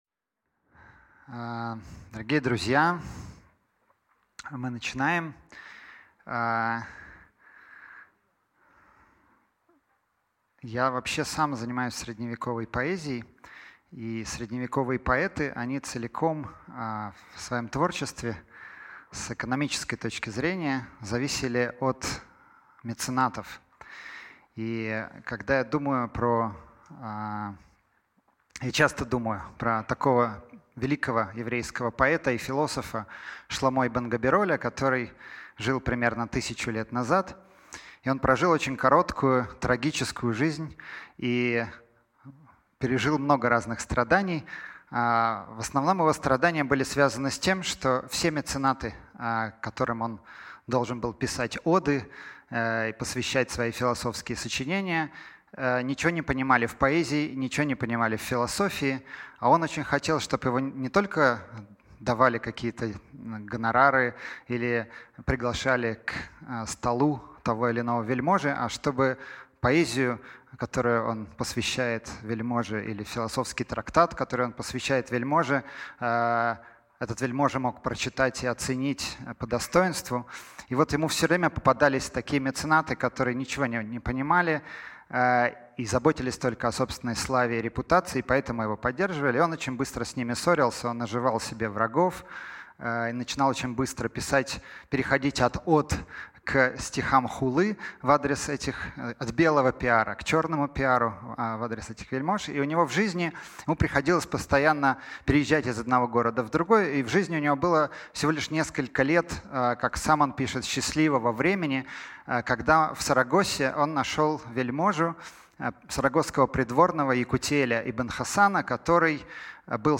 Аудиокнига Эфиопский Сион – символ и реальность | Библиотека аудиокниг